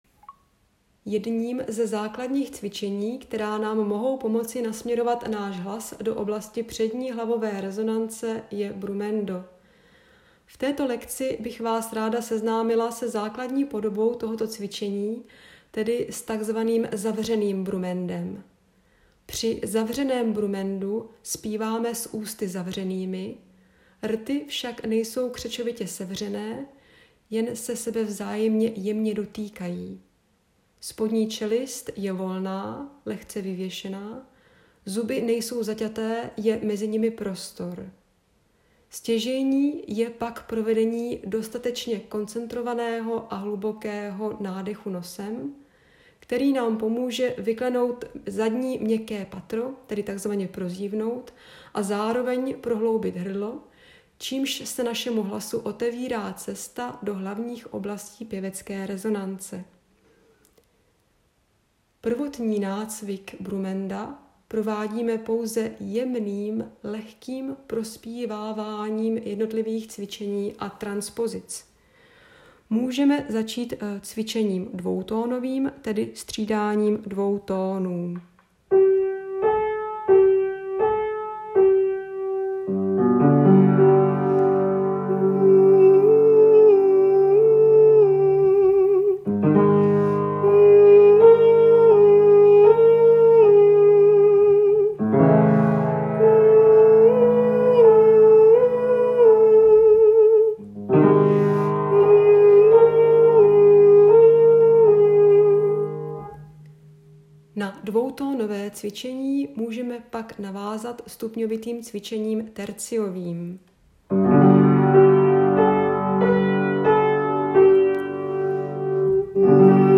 Brumendo_1.m4a